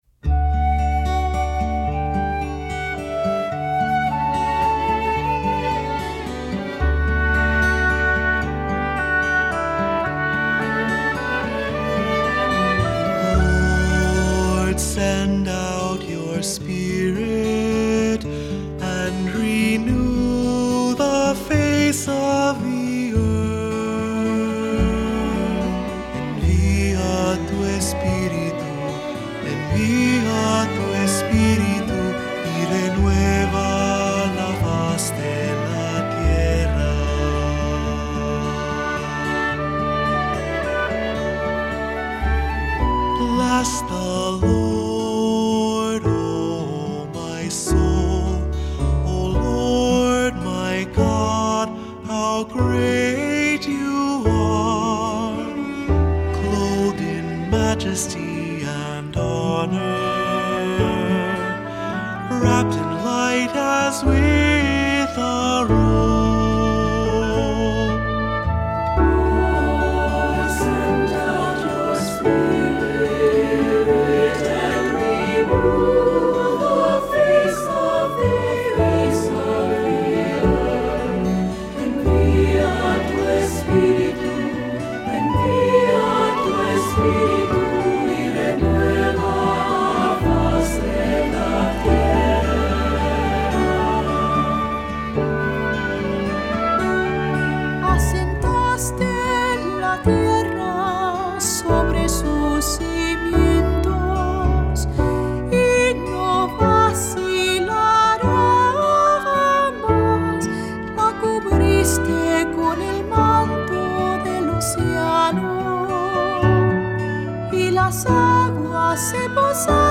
Voicing: SATB; Cantor; Assembly